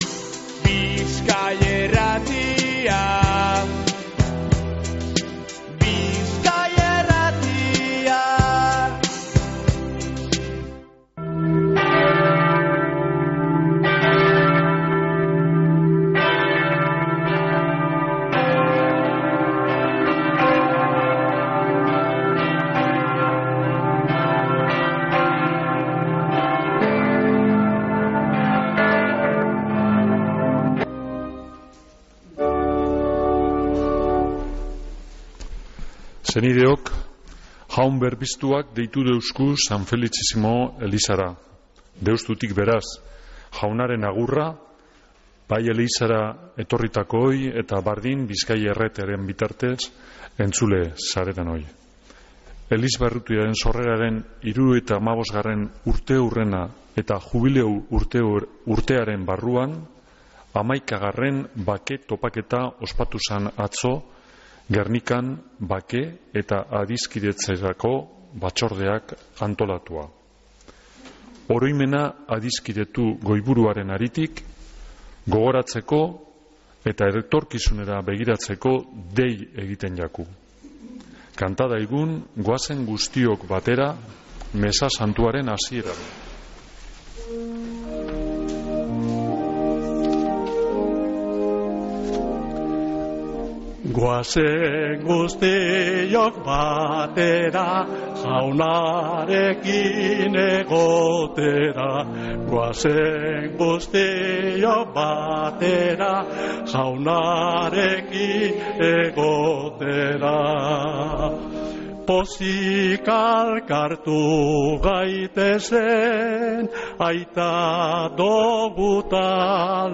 Mezea (25-02-23)